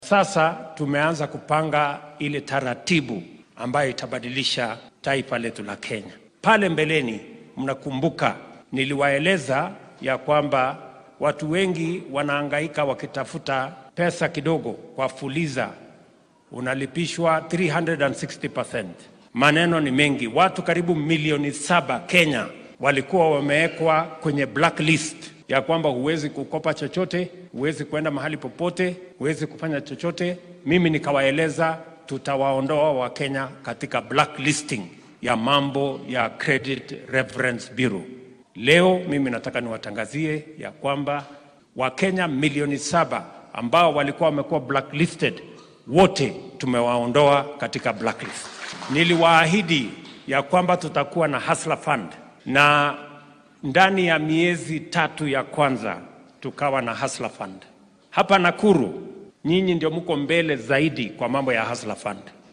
Madaxweynaha dalka William Ruto ayaa xilli uu ku sugnaa deegaan baarlamaneedka Molo ee ismaamulka Nakuru sheegay in ku dhawaad 7 milyan oo qof oo kenyaan ah